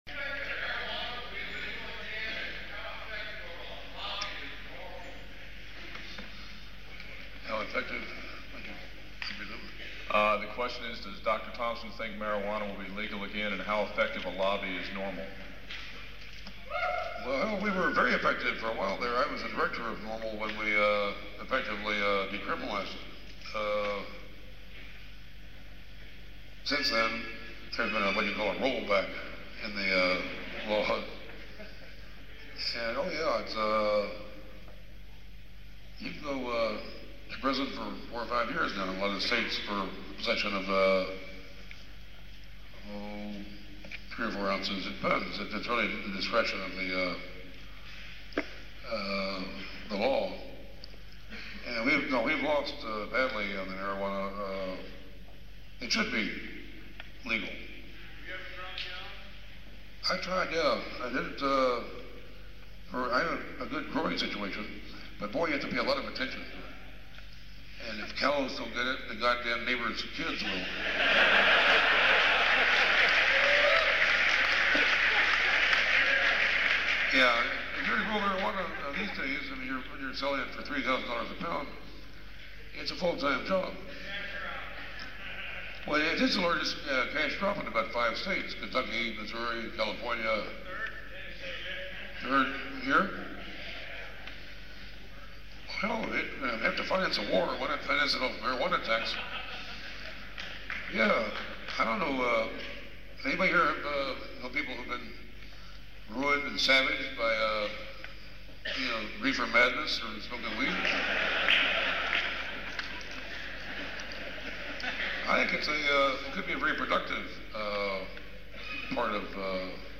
Hunter S. Thomson, Washington & Lee University part 2